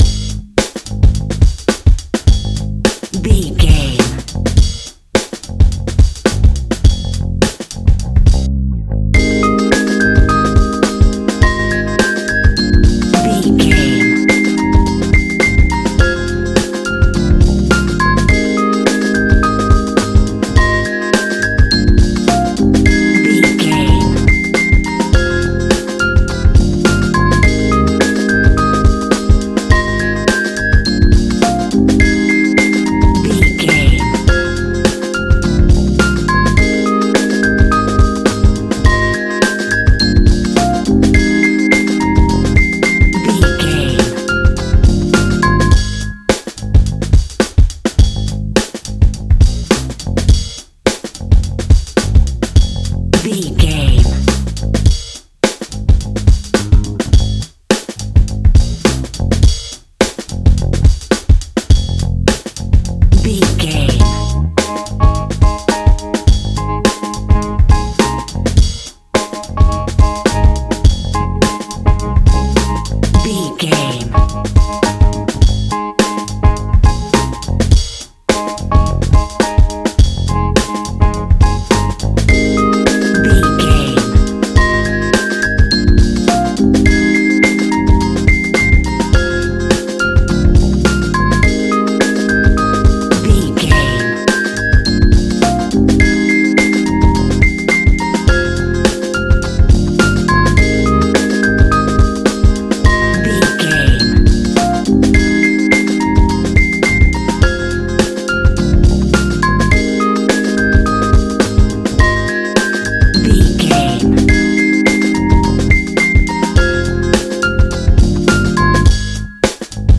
Ionian/Major
G♯
laid back
Lounge
sparse
new age
chilled electronica
ambient
atmospheric
morphing